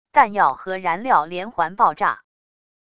弹药和燃料连环爆炸wav音频